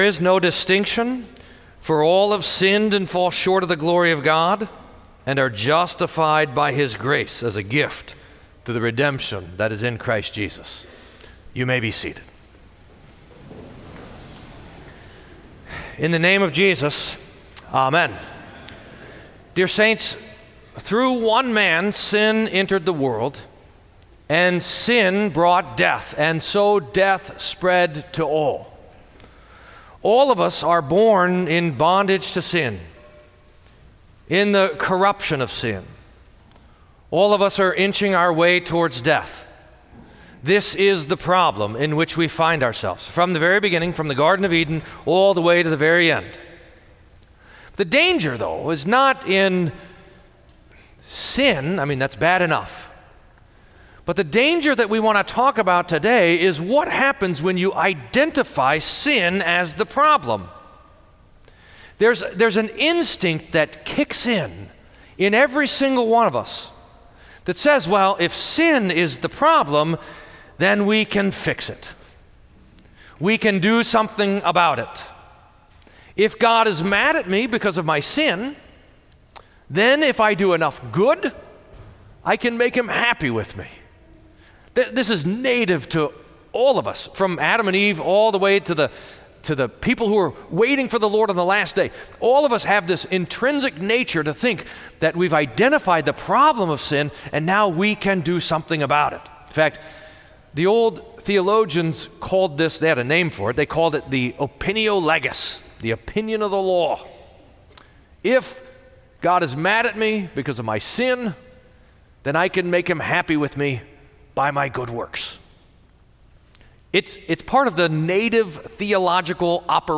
Sermon for Reformation